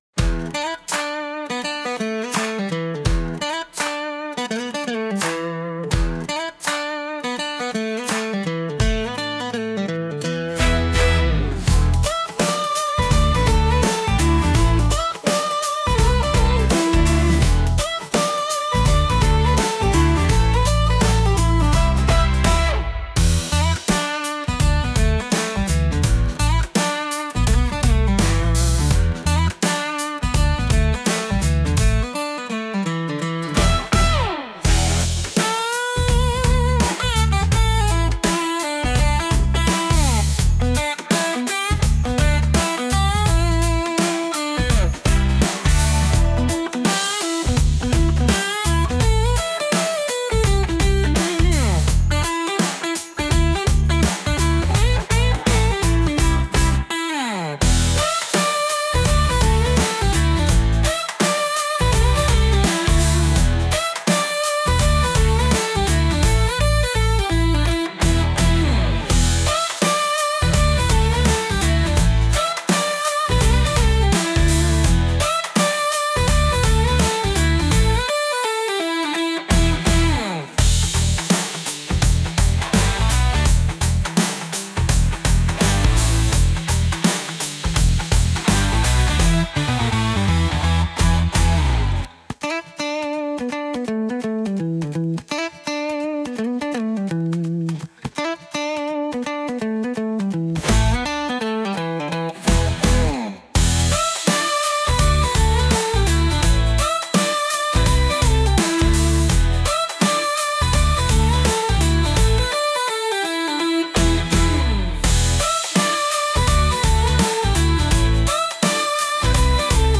とても退屈な様子を描いたBGM